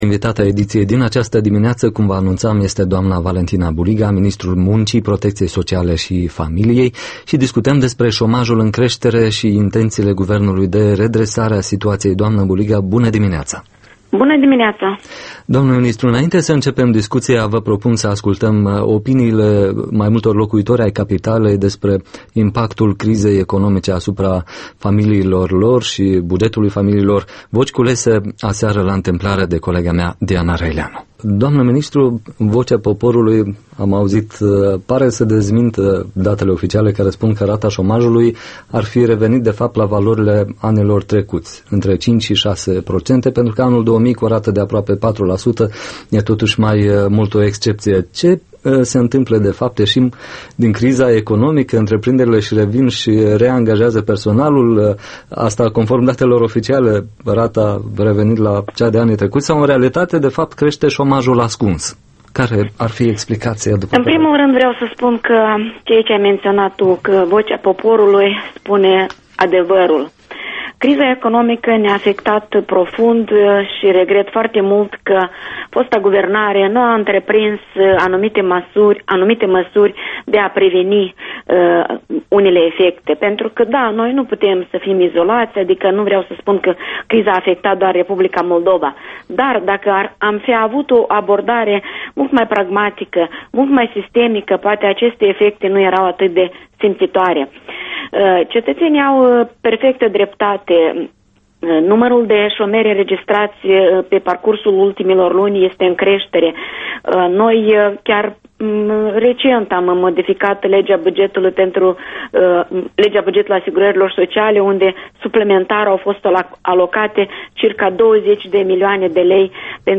Interviul matinal: cu Valentina Buliga